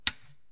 橱柜，门，按钮1 " 橱柜门锁点击1
描述：橱柜闩锁点击
Tag: 点击 橱柜